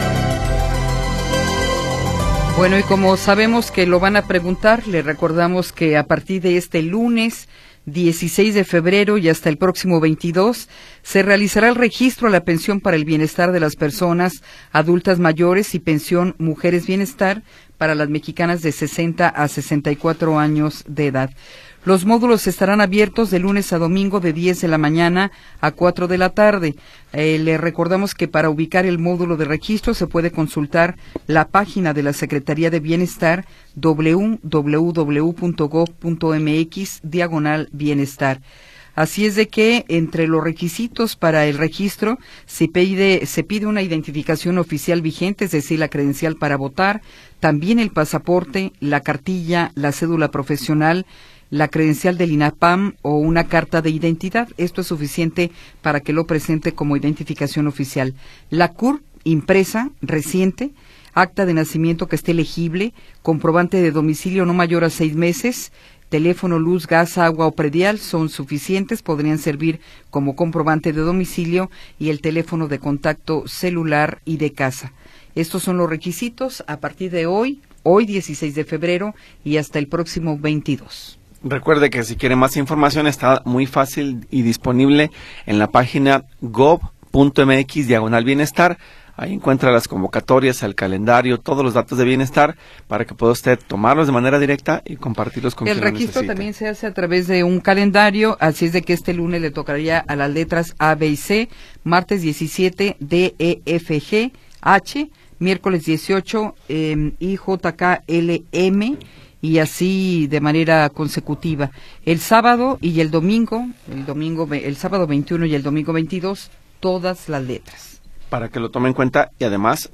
Segunda hora del programa transmitido el 16 de Febrero de 2026.